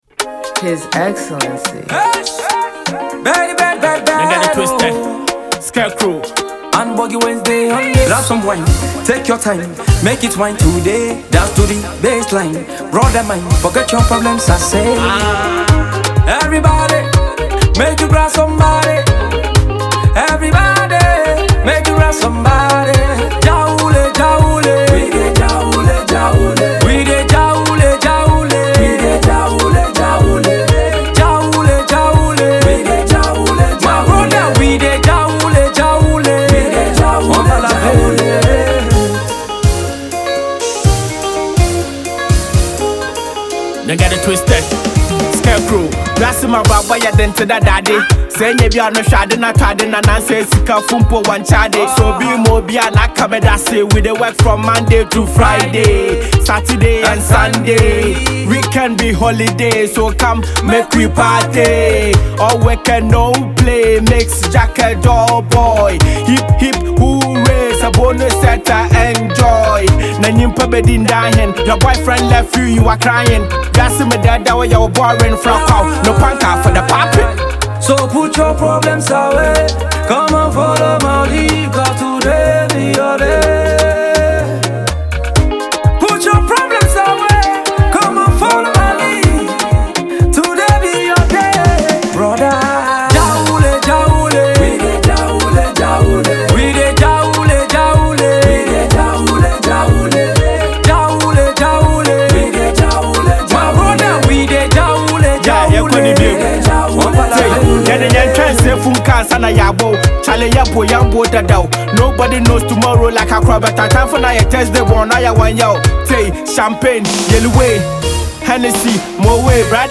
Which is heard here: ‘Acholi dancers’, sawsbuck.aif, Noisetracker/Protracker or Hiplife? Hiplife